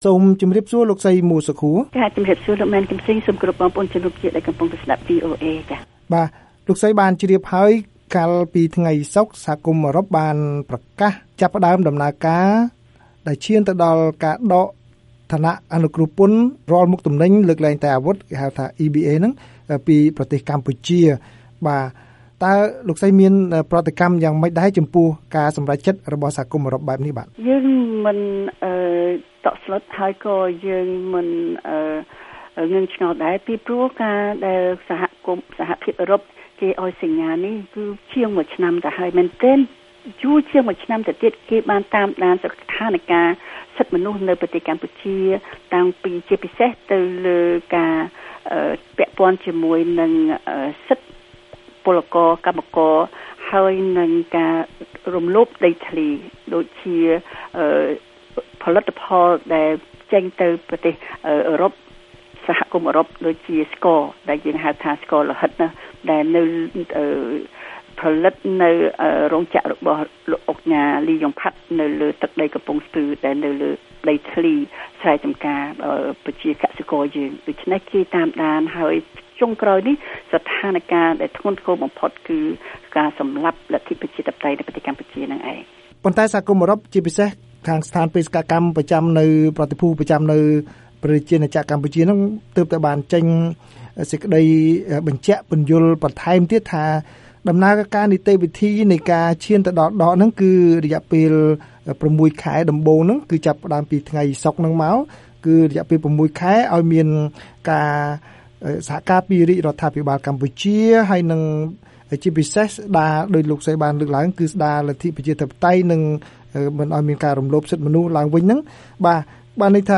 បទសម្ភាសន៍ VOA៖ សង្គ្រោះជាតិស្នើឱ្យមានការចរចាបញ្ចៀសទណ្ឌកម្មសេដ្ឋកិច្ចពី EU